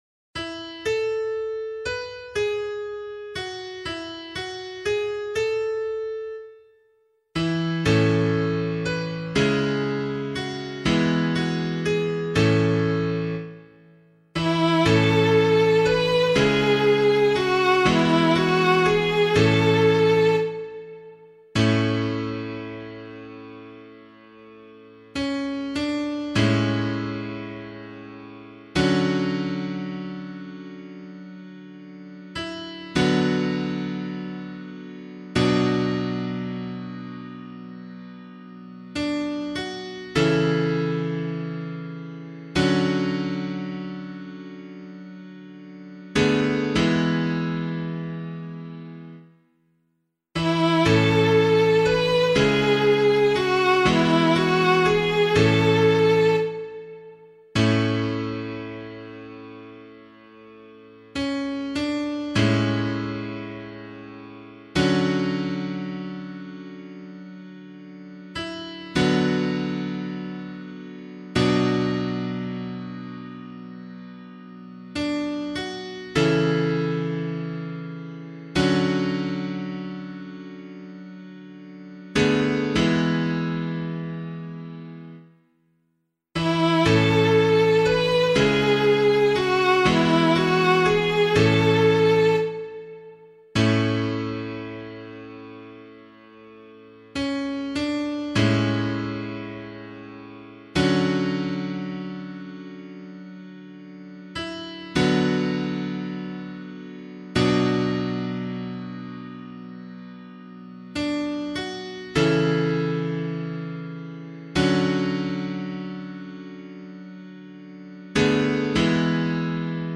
001 Advent 1 Psalm C [APC - LiturgyShare + Meinrad 8] - piano.mp3